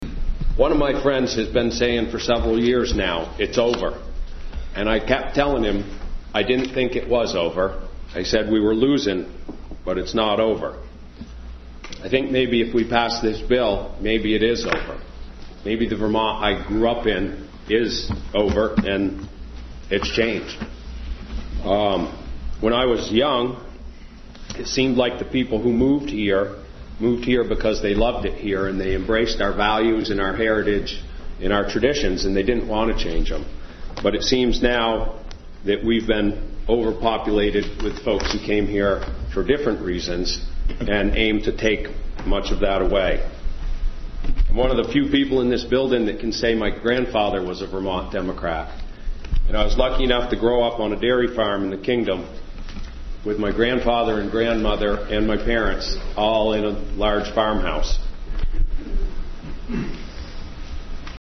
rodgers-senate.mp3